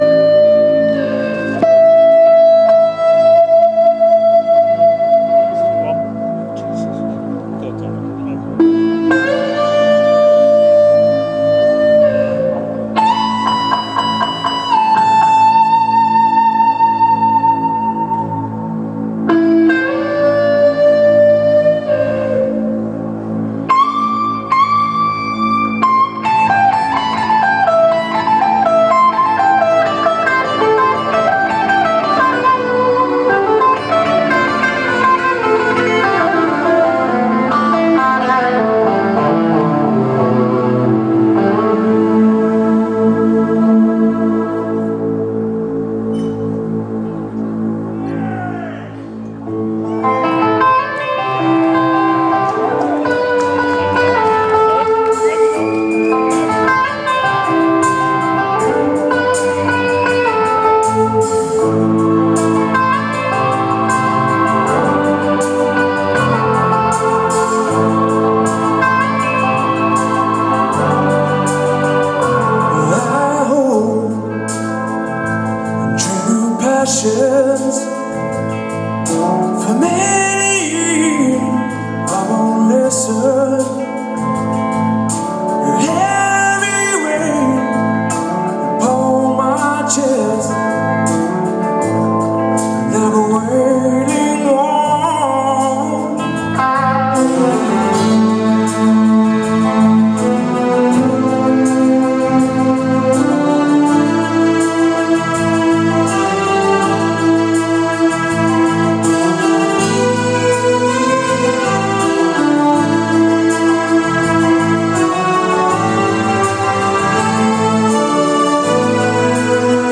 Including some not-so-subtle The Who influences